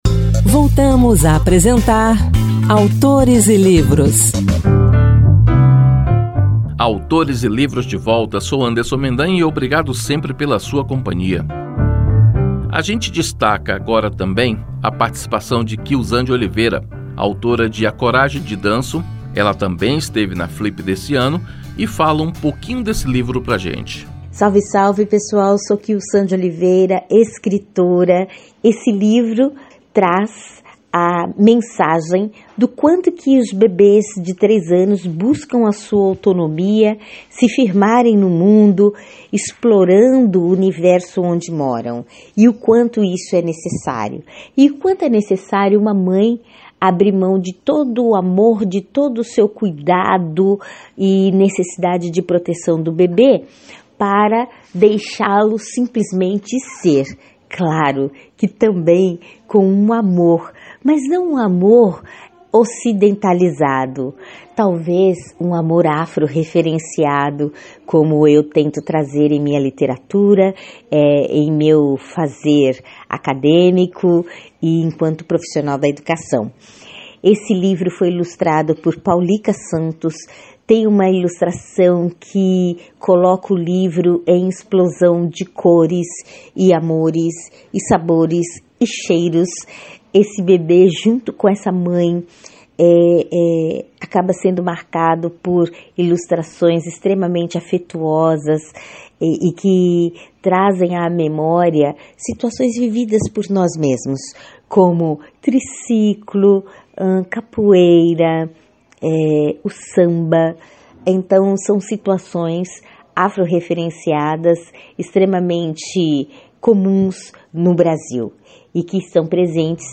Outro destaque no programa é a participação de diversos autores das editoras Elo e PeraBook falando de seus livros, da Flip e da importância da literatura para crianças e jovens.